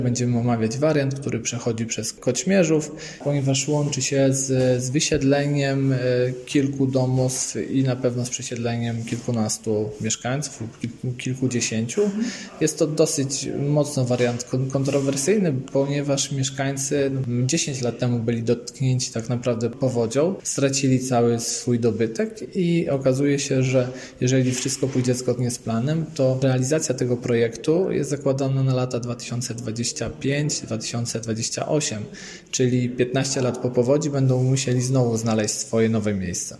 Teraz odbędzie się kolejne spotkanie z mieszkańcami. Mówi wiceprzewodniczący rady miasta w Sandomierzu Piotr Chojnacki: